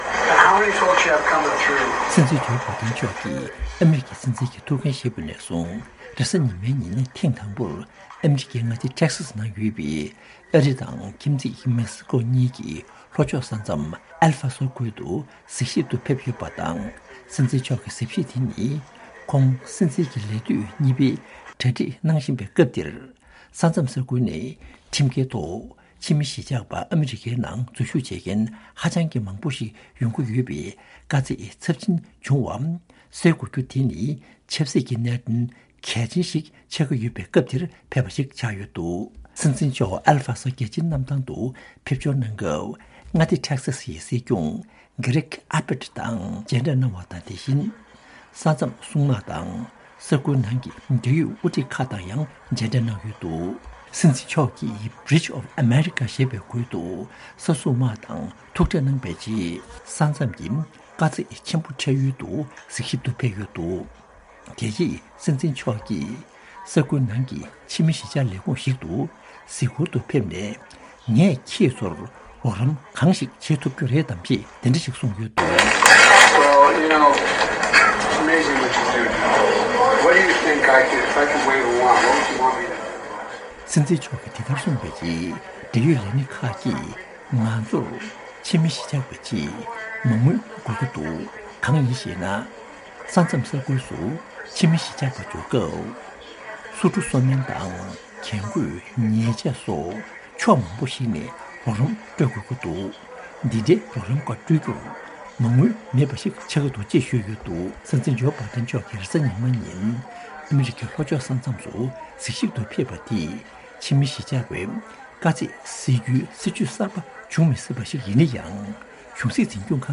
ཕྱོགས་བསྒྲིགས་དང་ཕབ་བསྒྱུར་སྙན་སྒྲོན་ཞུ་ཡི་རེད།